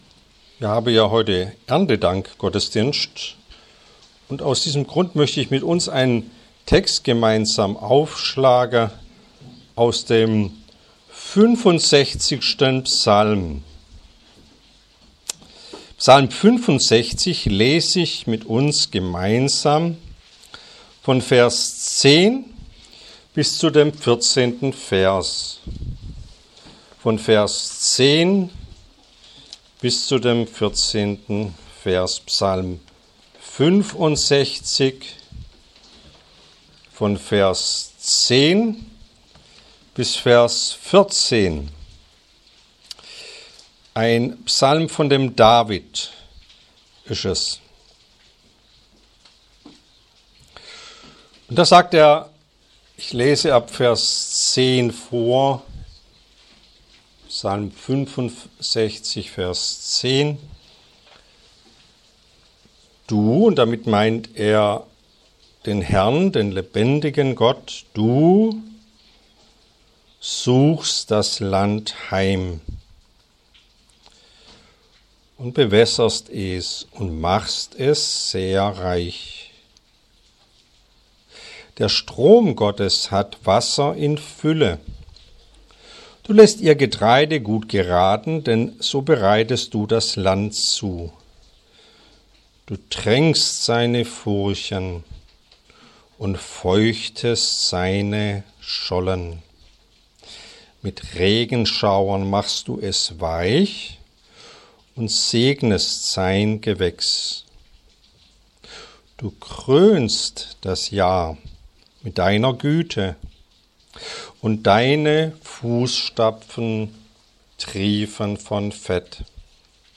Serie: Erntedankgottesdienst